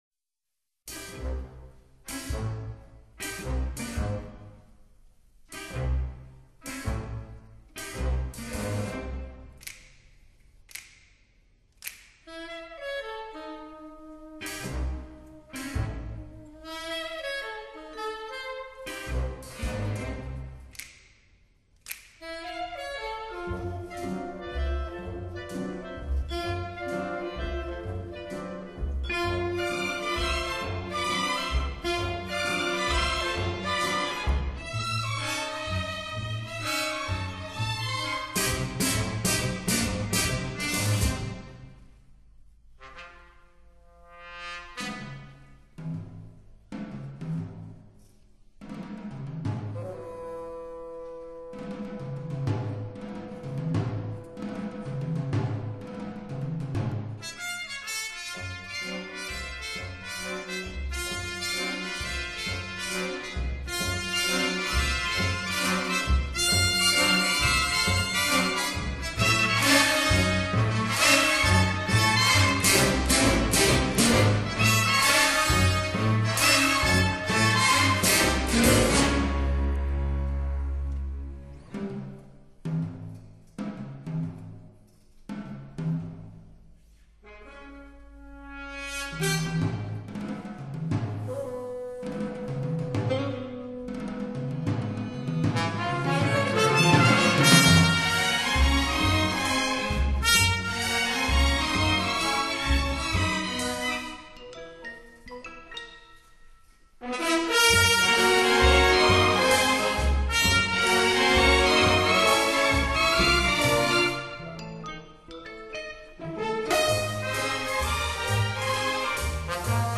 音乐类型:古典音乐